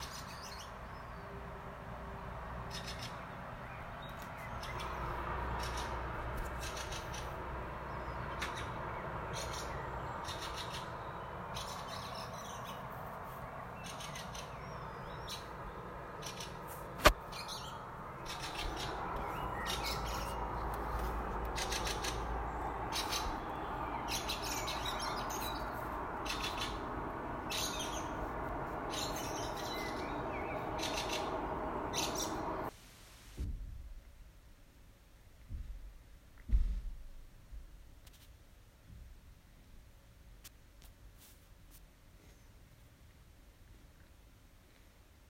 Ukjent fuglelyd
Bor i Bærum ved E18, derav suset i bakgrunnen.
Den kraftigste lyden her er skvatring fra en gråtrost, og så svarttrost i bakgrunnen.